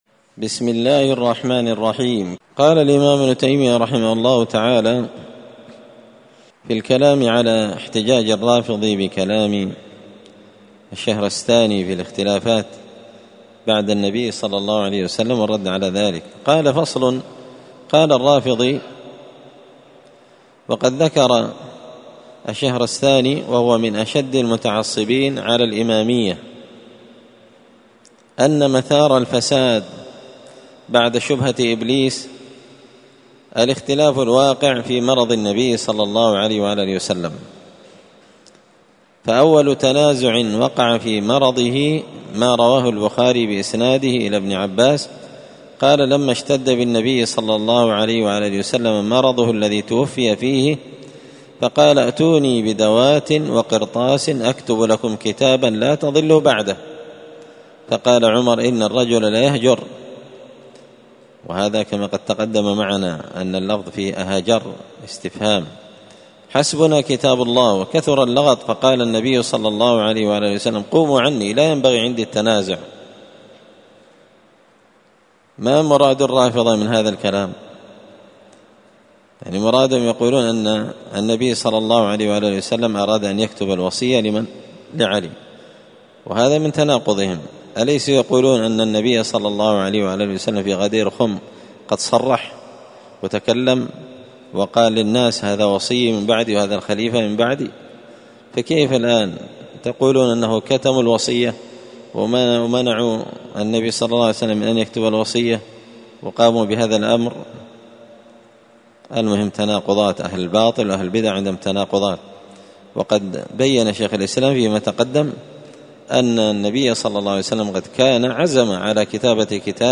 الأربعاء 1 محرم 1445 هــــ | الدروس، دروس الردود، مختصر منهاج السنة النبوية لشيخ الإسلام ابن تيمية | شارك بتعليقك | 11 المشاهدات
مسجد الفرقان قشن_المهرة_اليمن